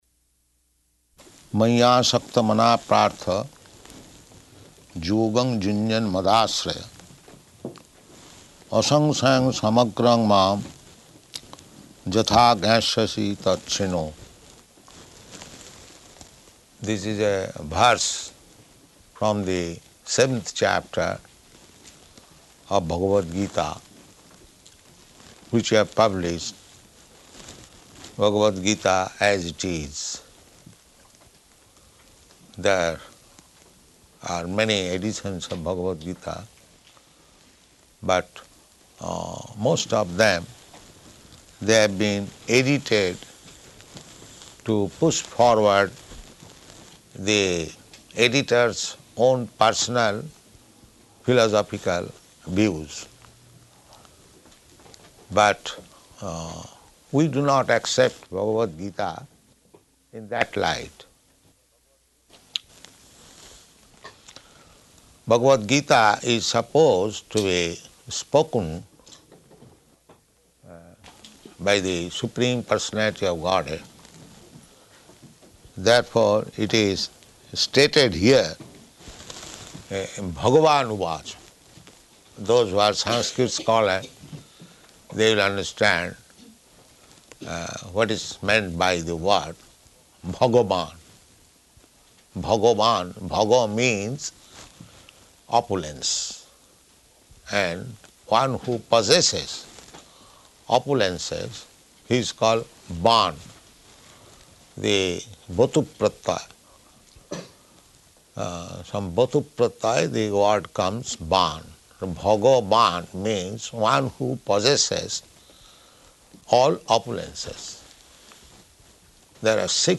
Location: Stockholm